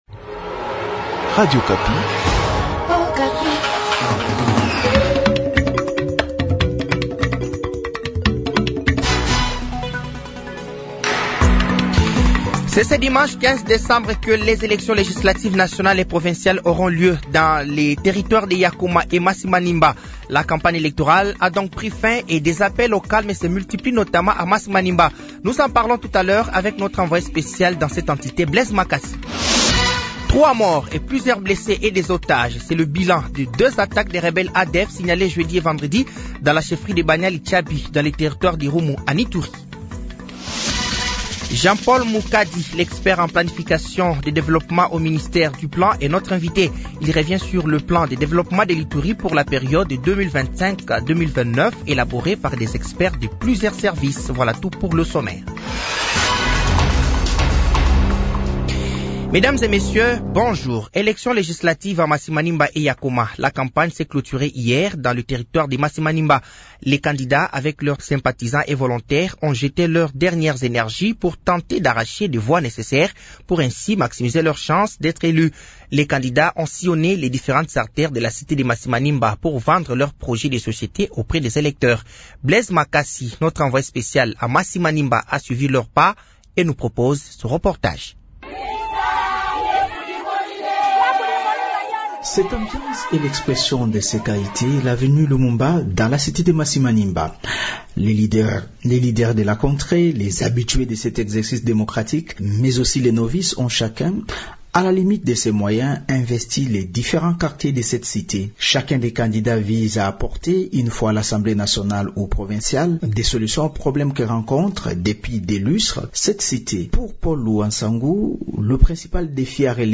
Journal français de 12h de ce samedi 14 décembre 2024